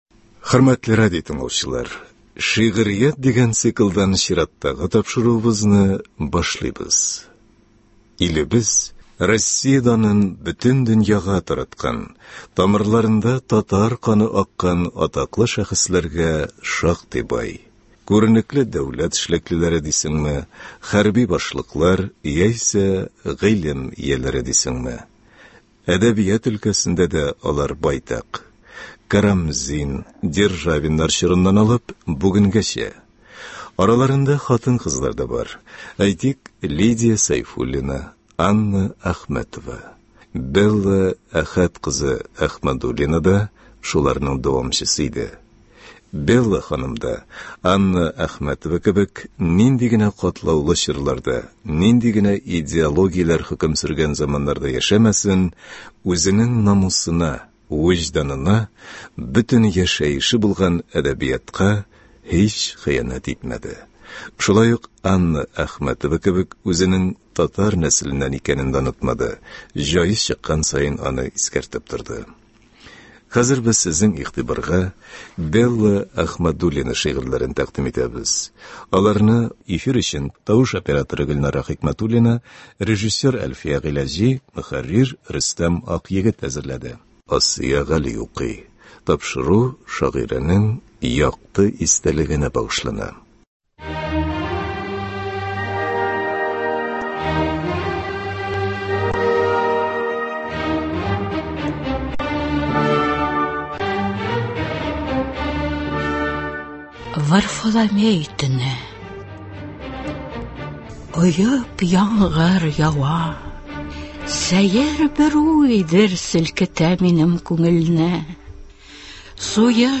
Радиокомпозиция.